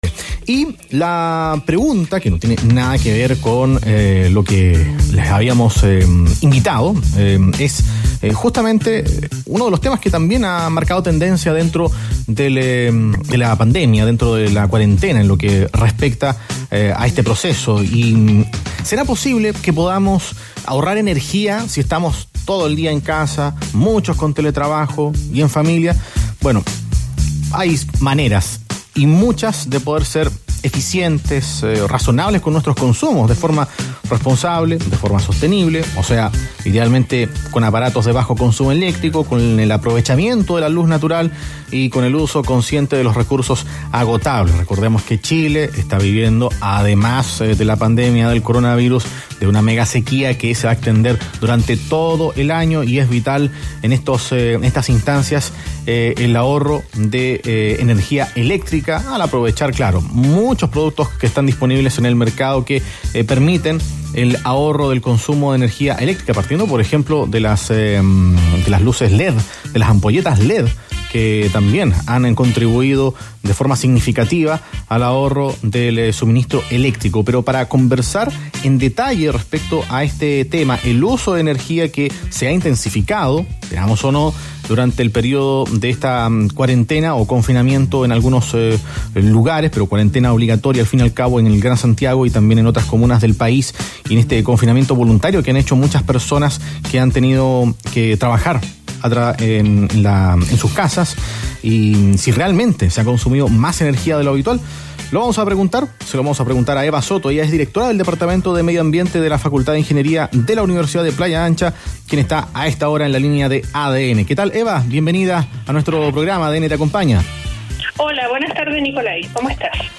En entrevista en ADN Radio